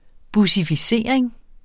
Udtale [ busifiˈseˀɐ̯eŋ ]